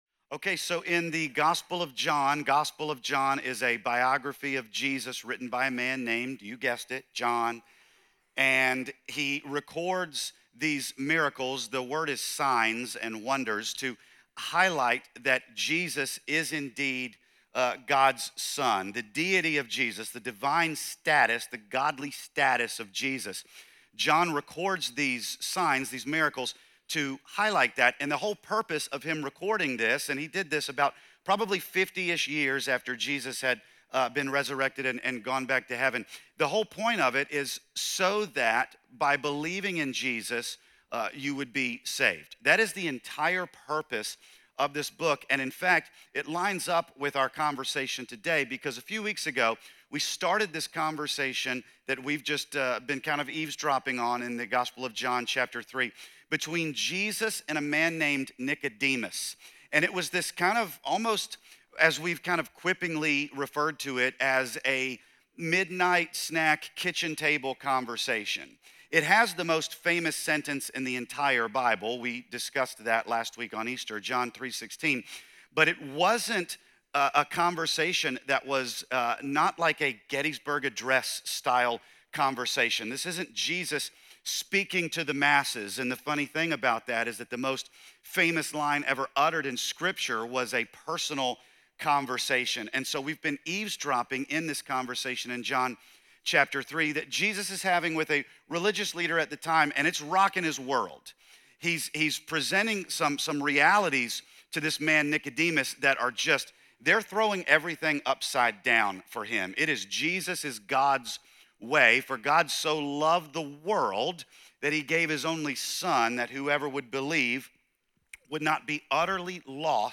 John 3:17-21 - Sonrise Church, Santee
This message continues exploring Jesus’ late-night conversation with Nicodemus, focusing on John 3:17–21.